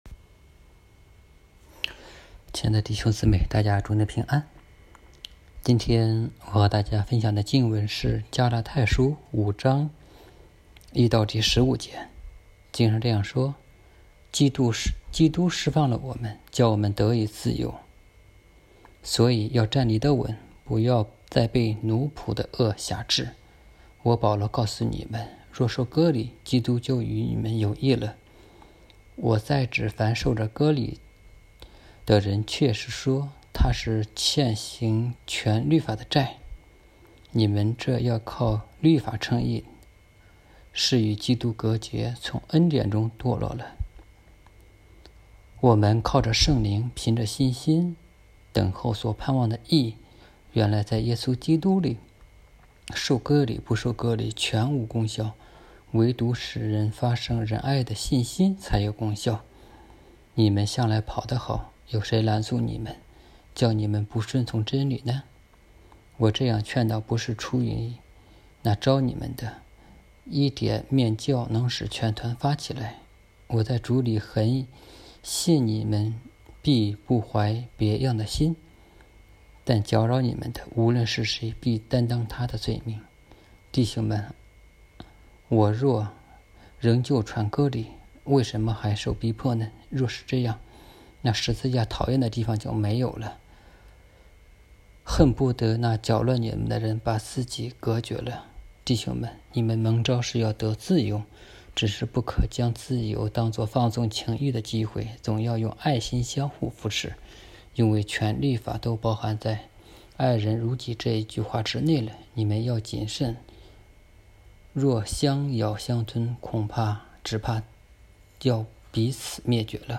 题目：《基督徒的生活-自由之一》 证道